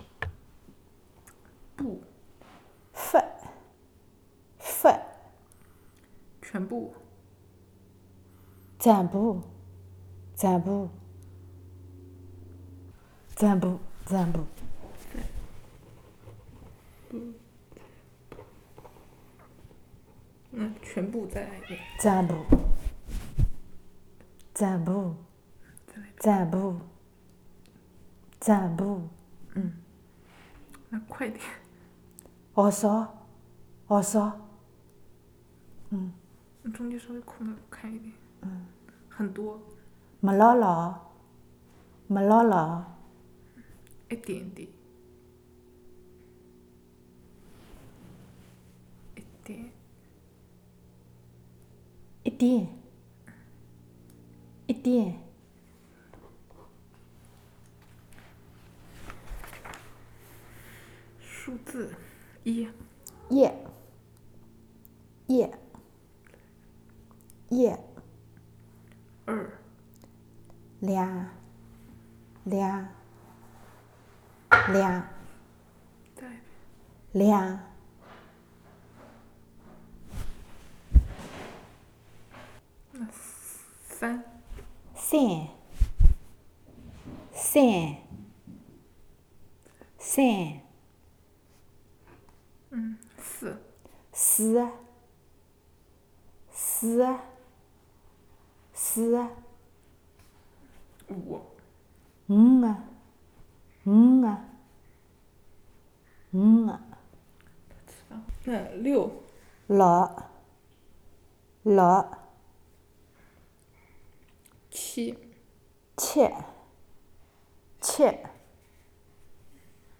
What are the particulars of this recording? digital wav file recorded at 44.1 kHz/16 bit on Zoom H4n Pro recorder Sanmen, Zhejiang Province, China; Xinchang, Zhejiang Province, China; recording made in USA